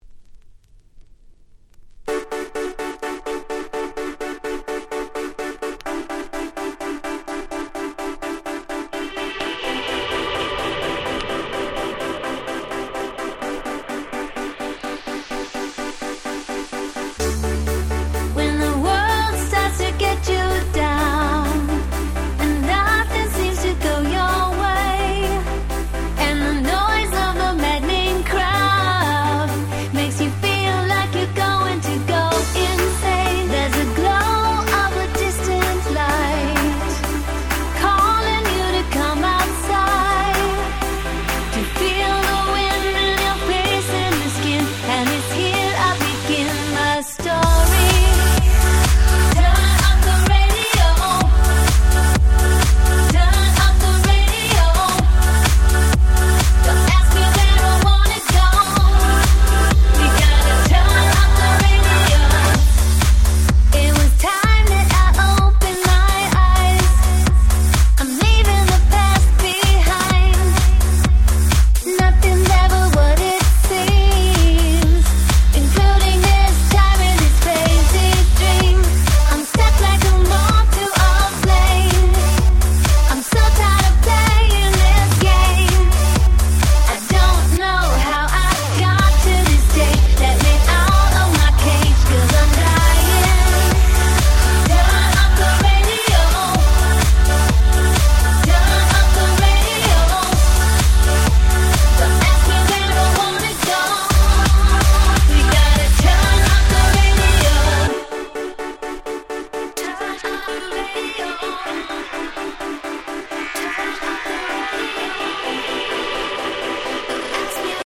12' Smash Hit Pops / R&B !!
当時のメインストリーム感全開のキャッチーでポップな四つ打ちダンスナンバー！！
EDM